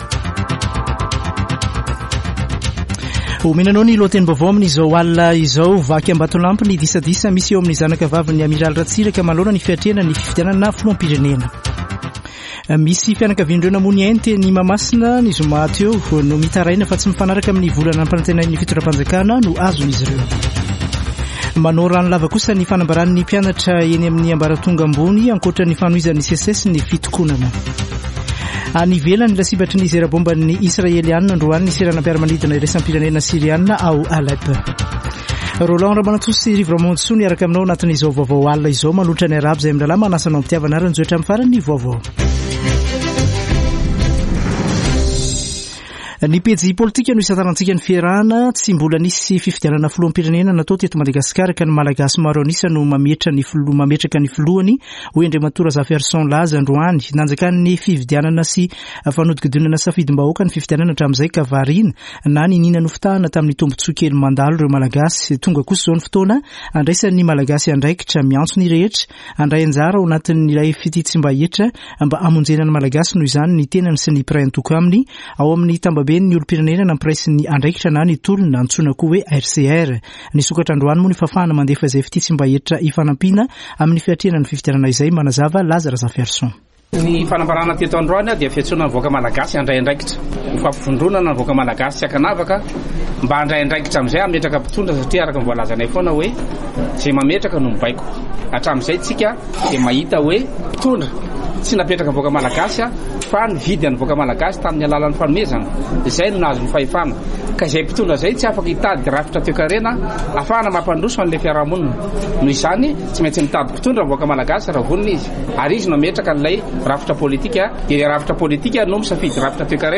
[Vaovao hariva] Alatsinainy 28 aogositra 2023